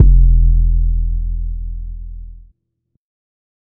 TC 808 2.wav